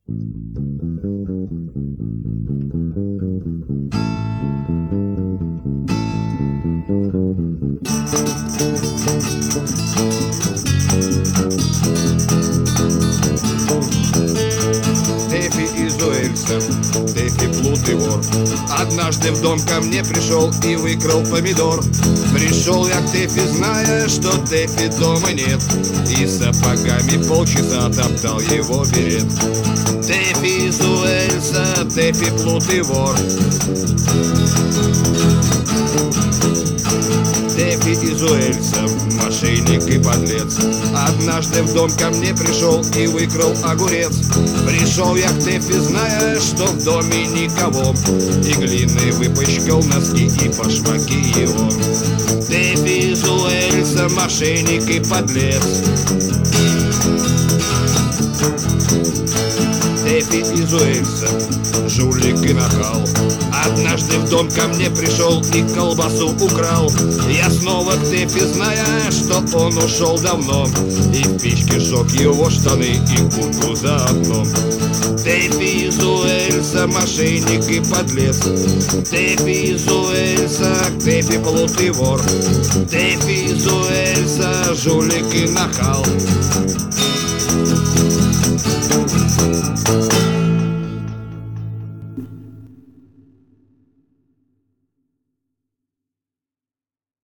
(микрофон висел на кипятильнике :)